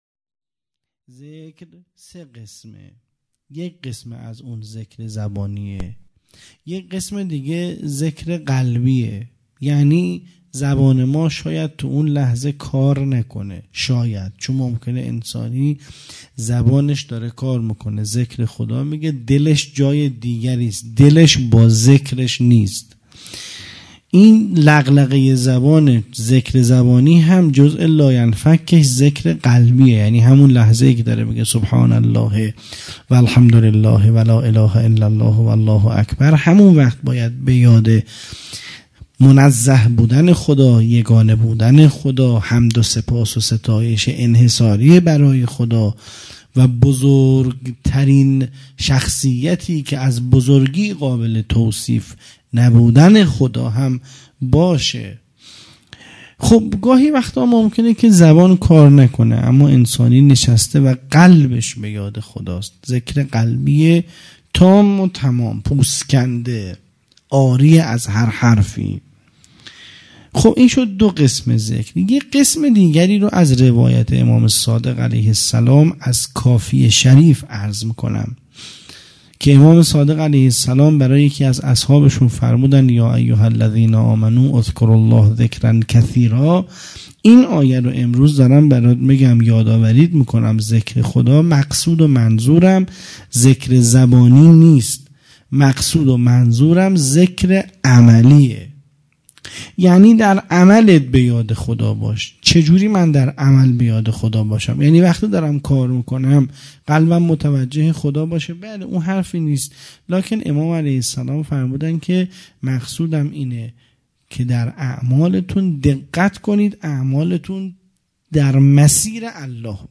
خیمه گاه - هیئت مکتب الزهرا(س)دارالعباده یزد